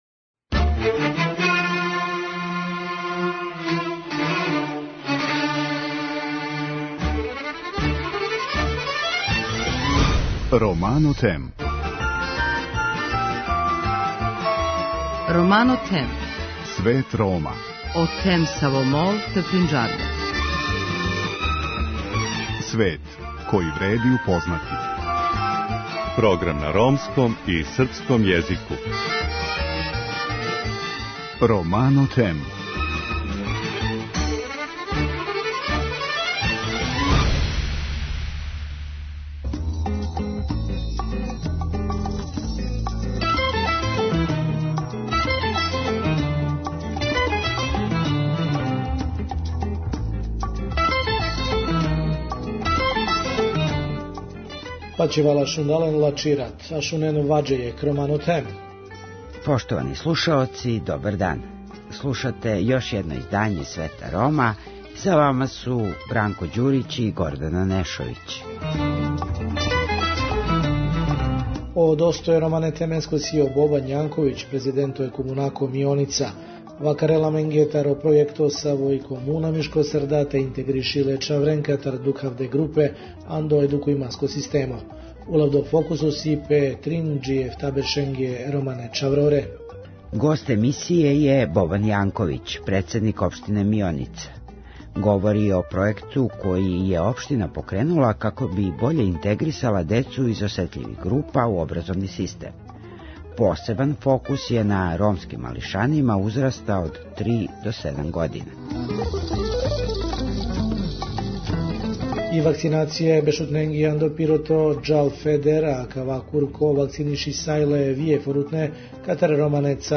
Гост Света Рома је Бобан Јанковић, председник Општине Мионица. Говори о пројекту који је општина покренула како би боље интегрисали децу из осетљивиш група у образовни систем. осебан фоксус је на ромским малишанима узраста од 3 до 7 година.